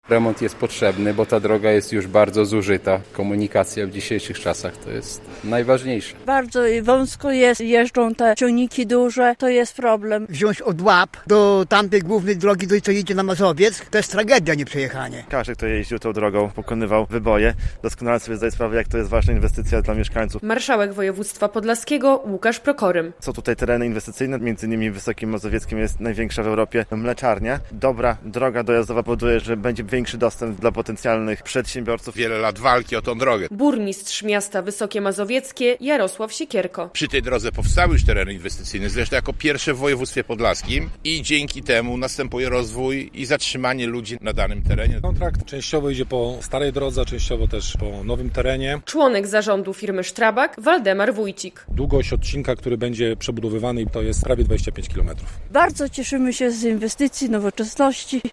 Uczestnicy wtorkowej uroczystości rozpoczęcia budowy podkreślali znaczenie tej drogi dla regionu.